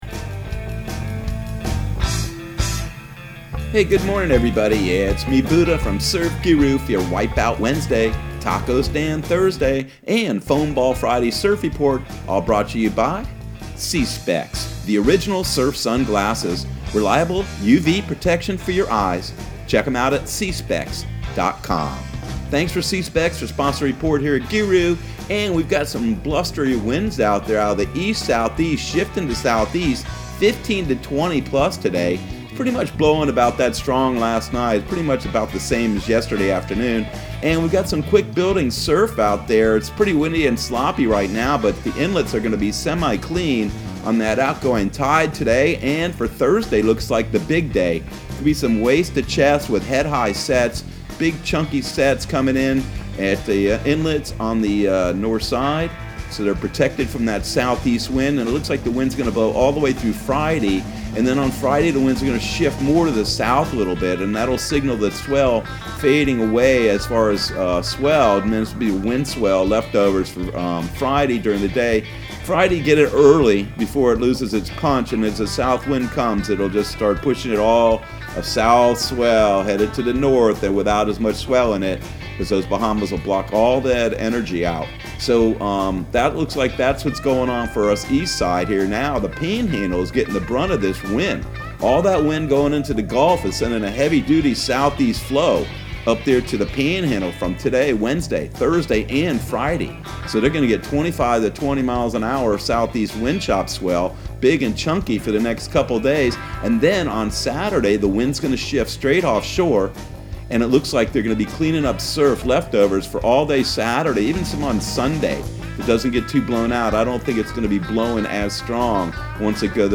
Surf Guru Surf Report and Forecast 12/30/2020 Audio surf report and surf forecast on December 30 for Central Florida and the Southeast.